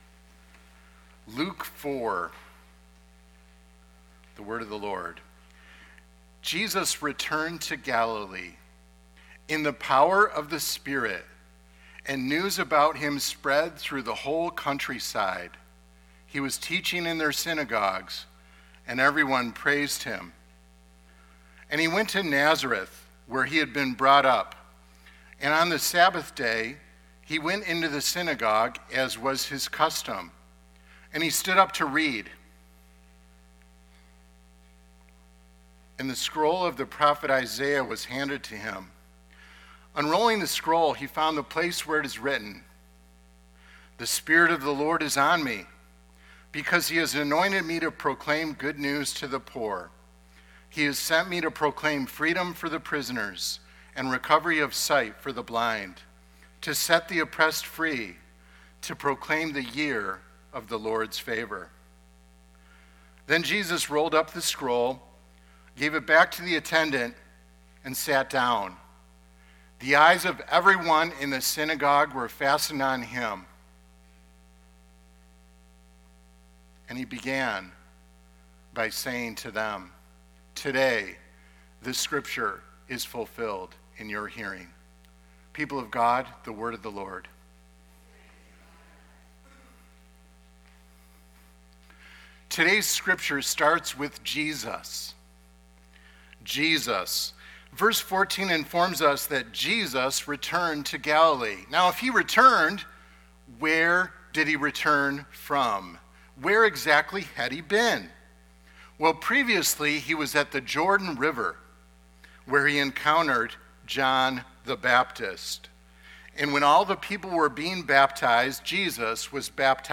Lakeview Sermon Podcast